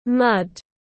Bùn tiếng anh gọi là mud, phiên âm tiếng anh đọc là /mʌd/.
Mud /mʌd/
mud.mp3